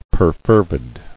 [per·fer·vid]